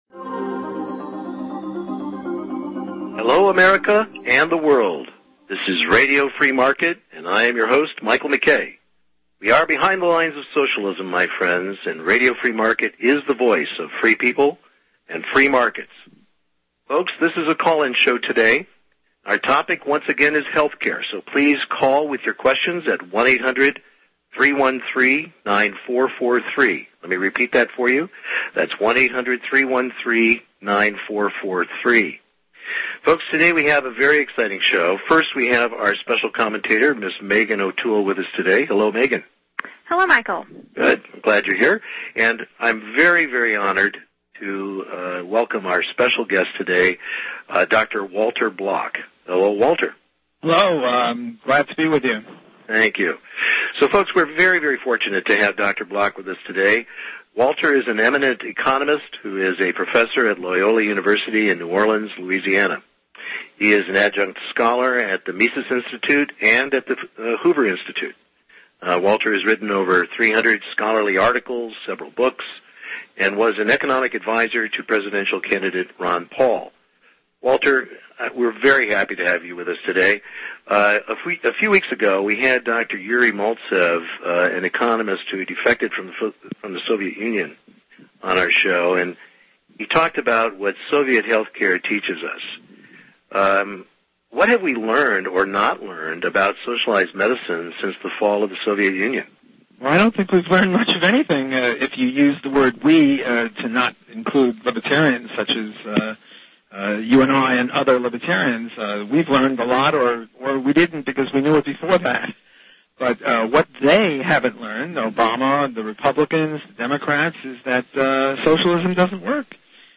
** Socialism Has Failed – So Let’s Try Socialism With Health Care! with Dr. Walter Block ** A Classic Interview with Dr. Walter Block kon Health Economics from the Free Market Perspective.